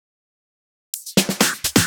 Fill 128 BPM (32).wav